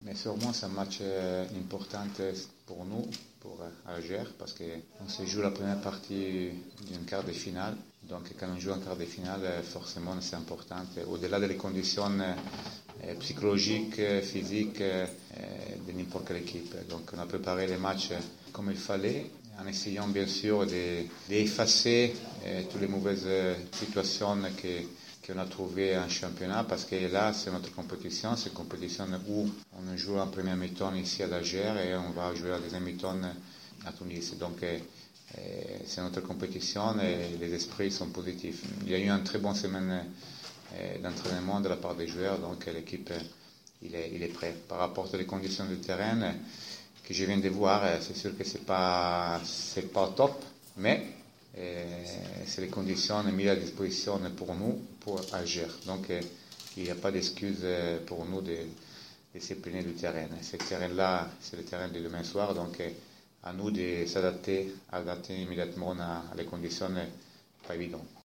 عقد الإطار الفني للنادي الإفريقي عشية اليوم ندوة صحفية للحديث حول المقابلة التي ستجمعه يوم غد السبت بفريق مولدية العاصمة الجزائري لحساب الدور ربع النهائي من كأس الإتحاد الإفريقي لكرة القدم و كان في الحضور المدرب ماركو سيموني .
ماركو سيموني : مدرب النادي الإفريقي